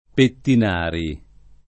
[ pettin # ri ]